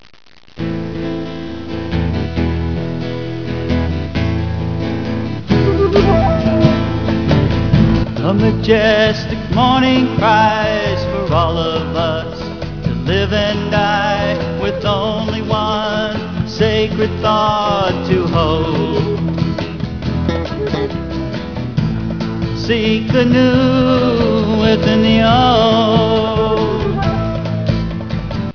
This was a folk-rock group